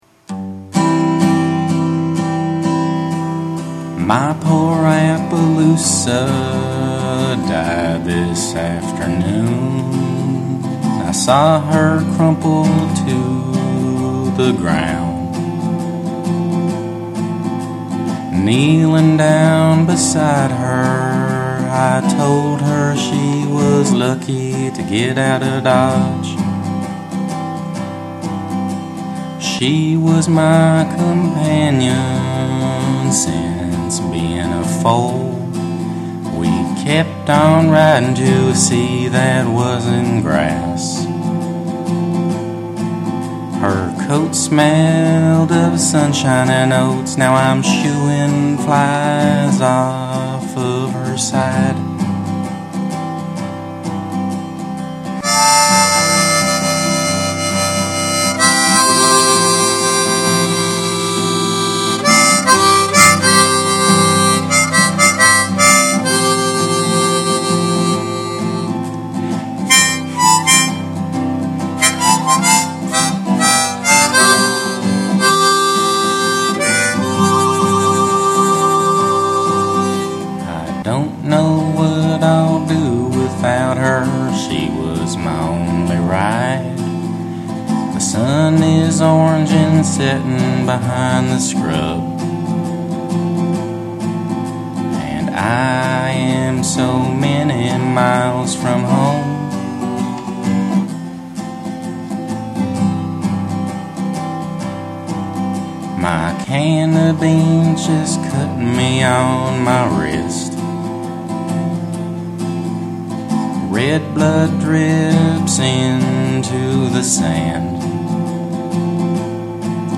A country song about a man and his horse